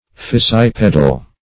fissipedal - definition of fissipedal - synonyms, pronunciation, spelling from Free Dictionary
Search Result for " fissipedal" : The Collaborative International Dictionary of English v.0.48: Fissiped \Fis"si*ped\, Fissipedal \Fis*sip"e*dal\, a. [Cf. F. fissip[`e]de.]